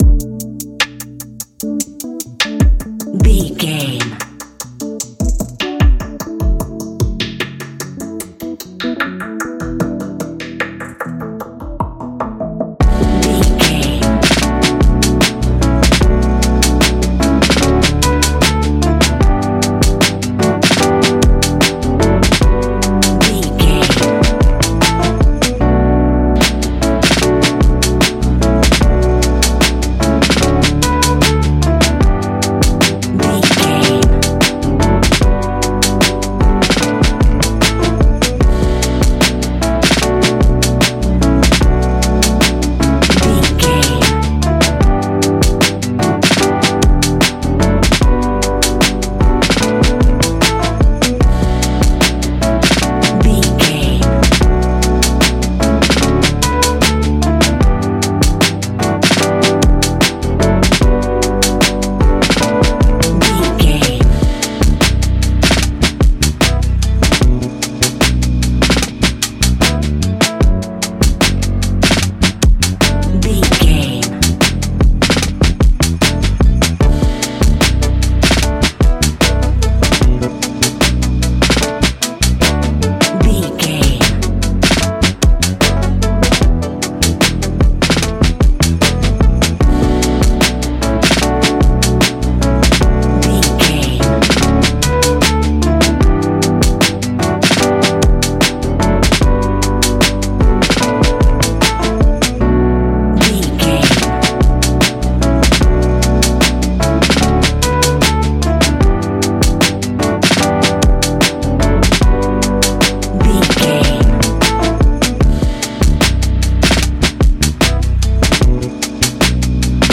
Ionian/Major
B♭
chilled
laid back
Lounge
sparse
new age
chilled electronica
ambient
atmospheric
instrumentals